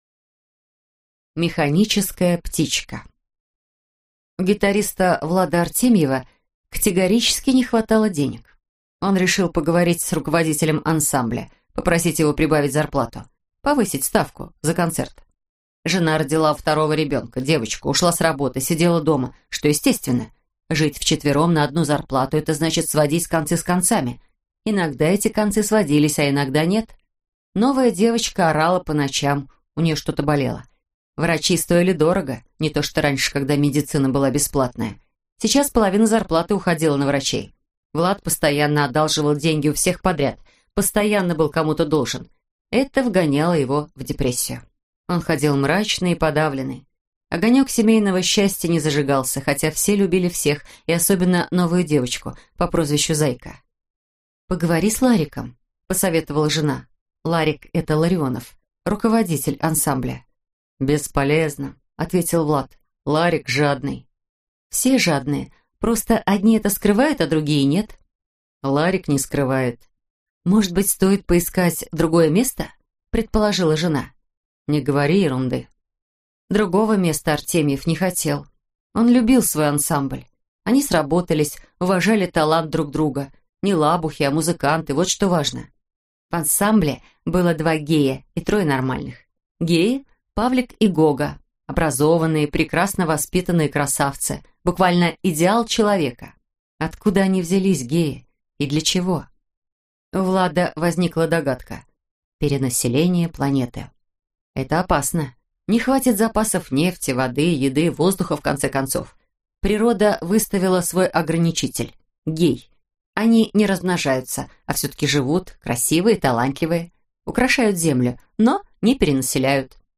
Аудиокнига Так плохо, как сегодня (сборник) | Библиотека аудиокниг